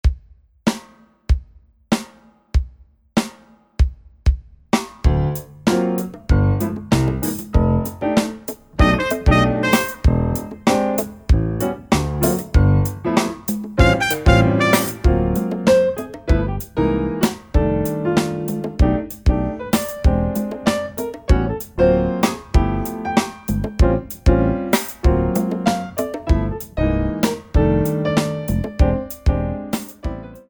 Hip-Hop Jazz
4 bar intro
allegretto